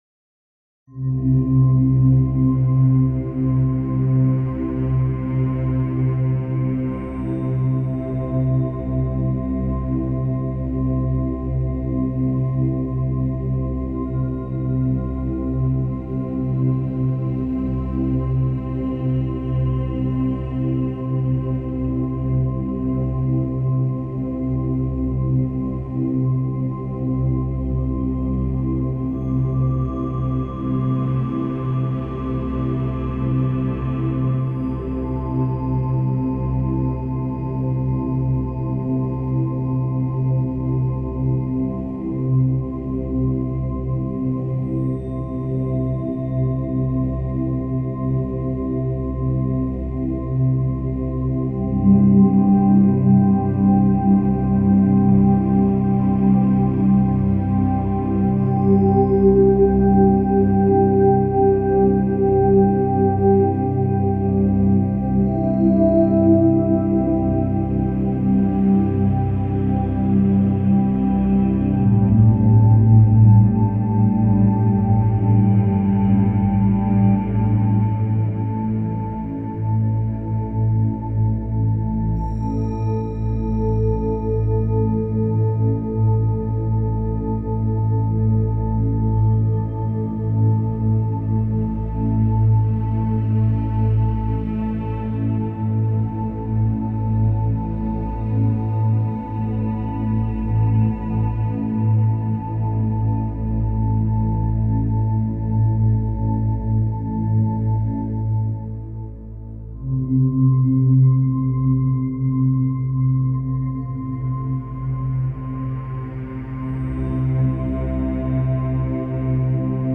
meadow2.opus